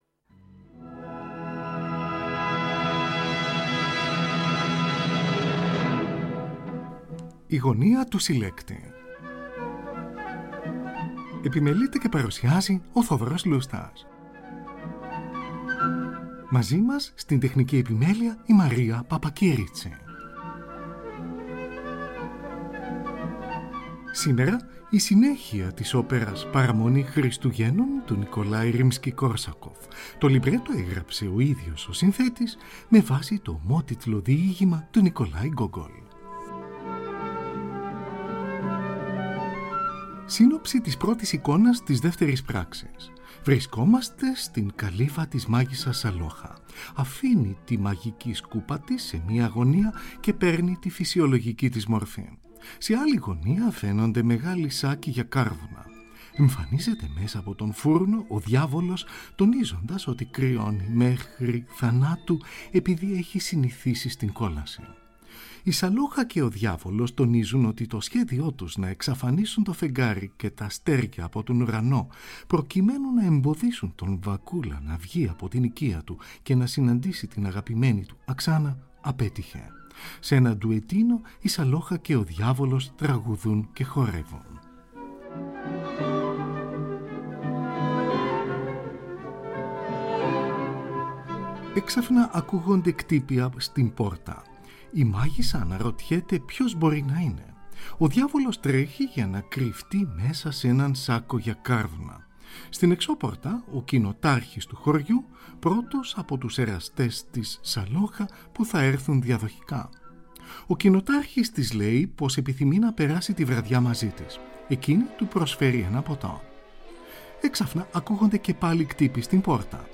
Πρώτη παγκόσμια ηχογράφηση .